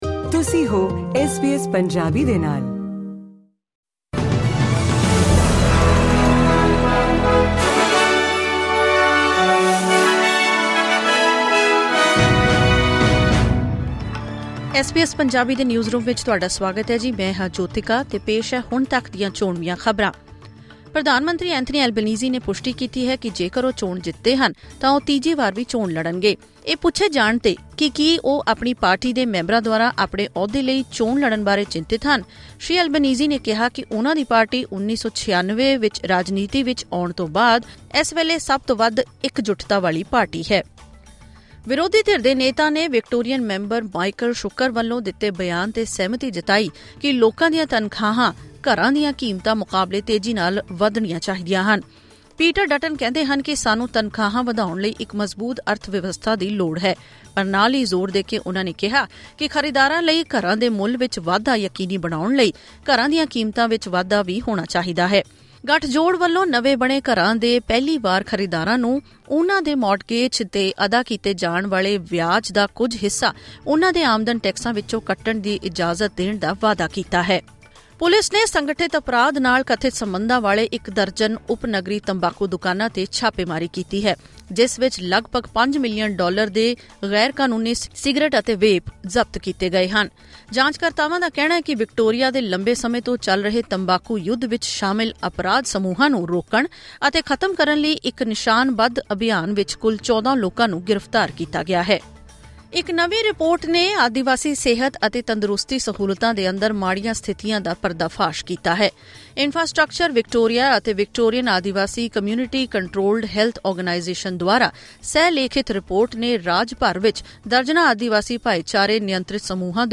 ਖ਼ਬਰਨਾਮਾ: ਅਲਬਾਨੀਜ਼ੀ ਨੇ ਤੀਸਰੀ ਵਾਰੀ ਚੋਣ ਲੜਨ ਦਾ ਦਿੱਤਾ ਸੰਕੇਤ, ਕਿਹਾ 'ਪਾਰਟੀ ਵਿੱਚ ਪੂਰੀ ਇੱਕਜੁੱਟਤਾ'